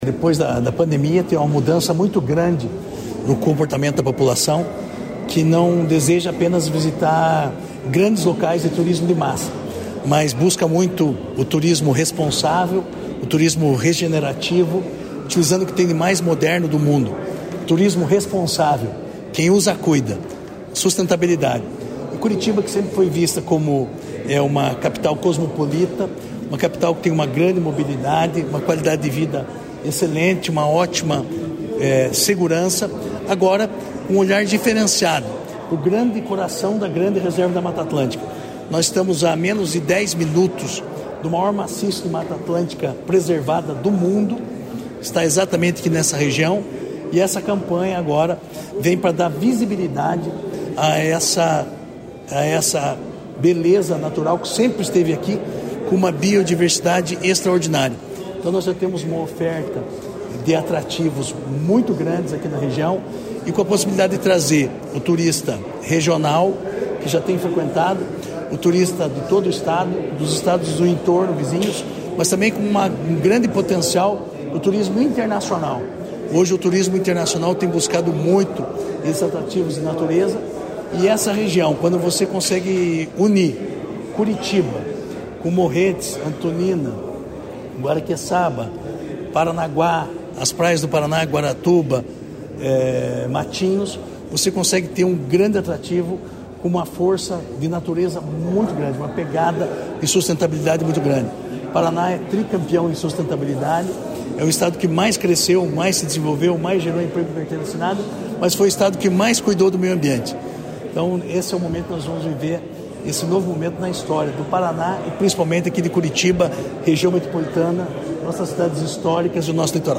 Sonora do secretário Estadual do Turismo, Marcio Nunes, sobre o lançamento da rota de turismo sustentável no Litoral em parceria com o Airbnb